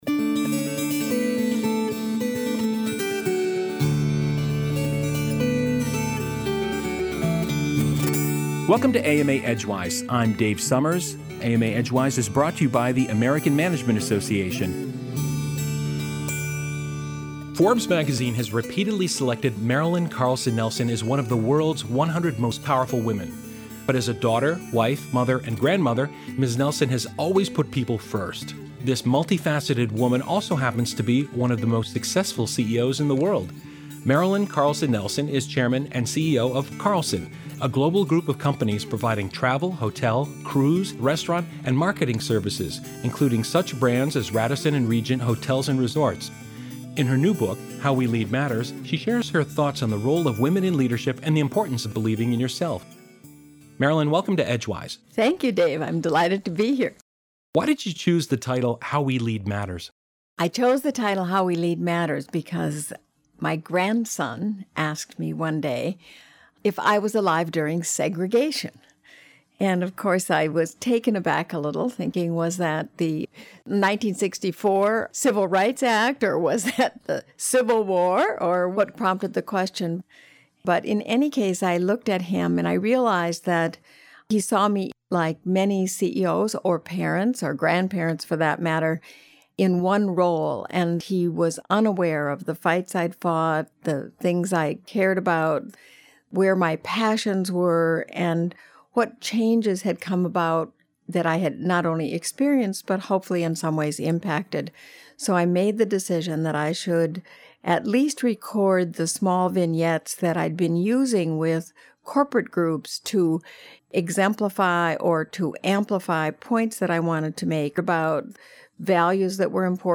In this conversation, she shares her thoughts on the role of women in leadership and the importance of believing in yourself.